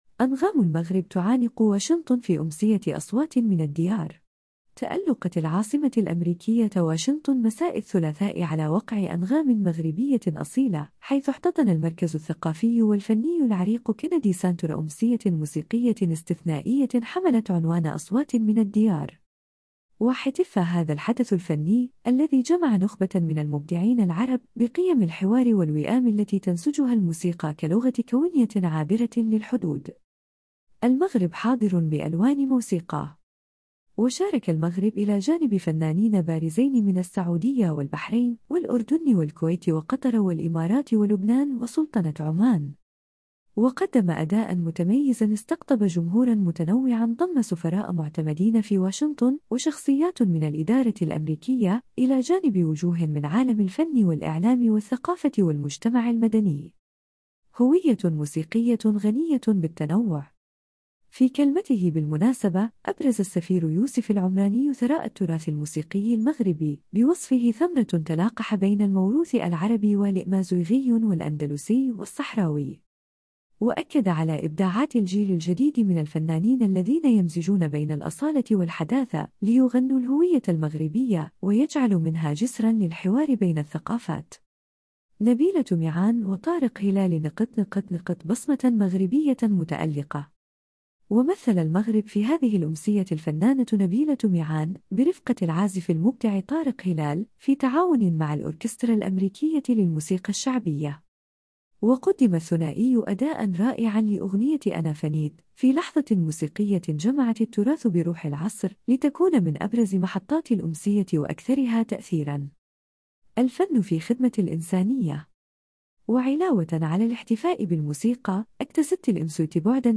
تألقت العاصمة الأمريكية واشنطن مساء الثلاثاء على وقع أنغام مغربية أصيلة، حيث احتضن المركز الثقافي والفني العريق “كينيدي سانتر” أمسية موسيقية استثنائية حملت عنوان “أصوات من الديار”.
وقدّم الثنائي أداءً رائعًا لأغنية “أنا فنيت”، في لحظة موسيقية جمعت التراث بروح العصر، لتكون من أبرز محطات الأمسية وأكثرها تأثيرًا.